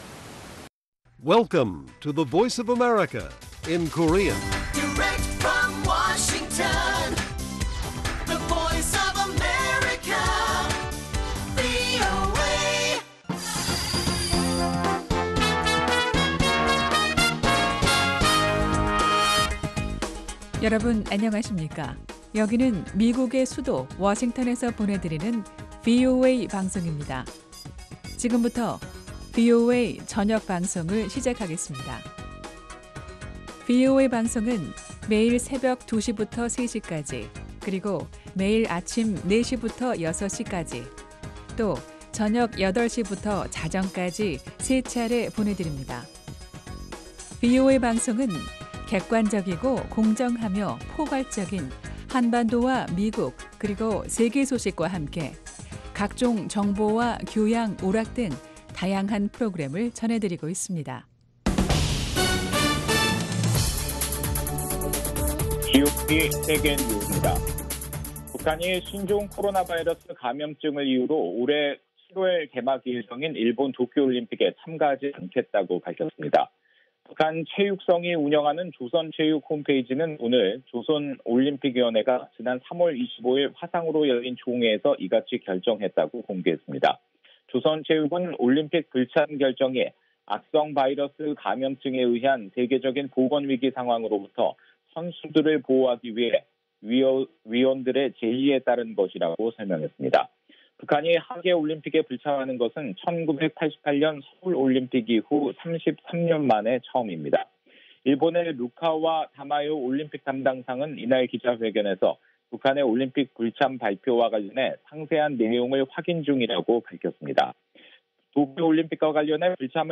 VOA 한국어 간판 뉴스 프로그램 '뉴스 투데이', 2021년 4월 6일 1부 방송입니다. 북한이 신종 코로나바이러스 감염증 사태를 이유로 오는 7월 열릴 예정인 도쿄 올림픽에 참가하지 않겠다로 밝혔습니다. 미국 내 한반도 전문가들은 바이든 행정부가 북한 비핵화 해법으로 단계적 조치를 추진할 것으로 전망했습니다. 북한 해커들이 사이버 보안연구원들을 노린 해킹 공격을 위해 허위 웹사이트까지 만들었다는 지적이 나왔습니다.